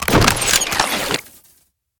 holster1.ogg